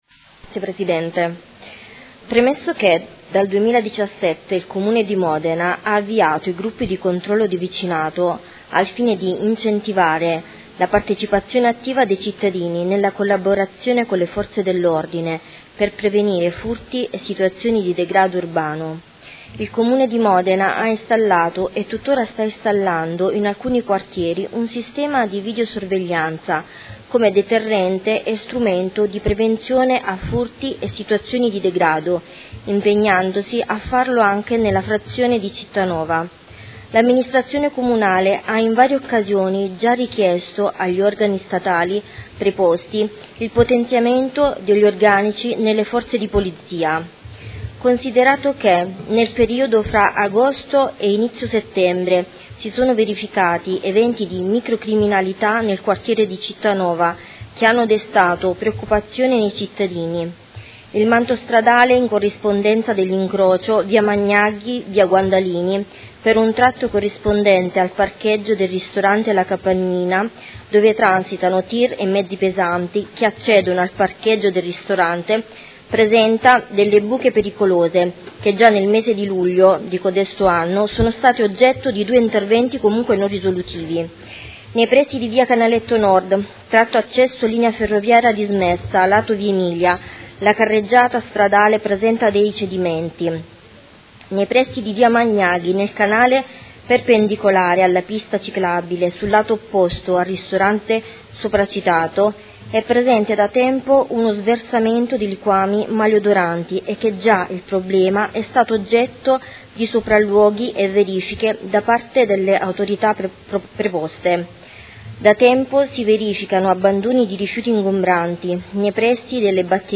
Seduta del 14/11/2019. Interrogazione dei Consiglieri Parisi (Modena Solidale) e Stefano Manicardi (PD) avente per oggetto: Problematiche frazione Cittanova